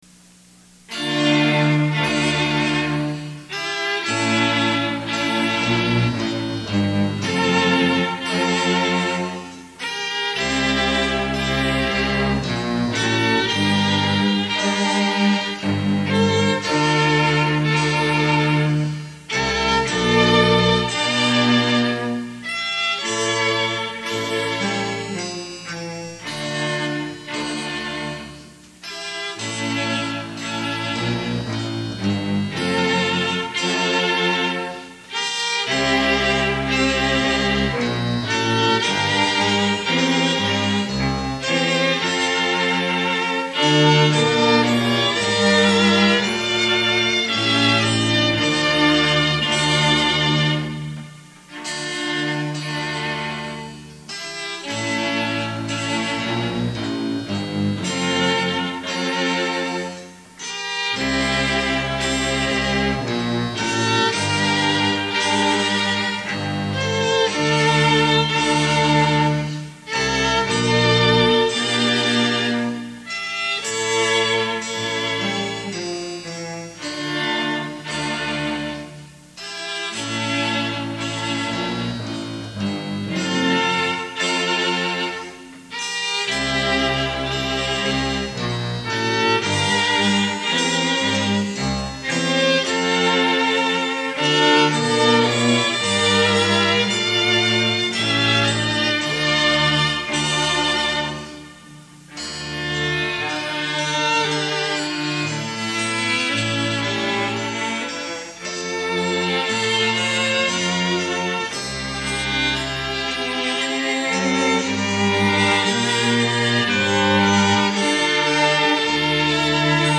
Voicing: SO Set C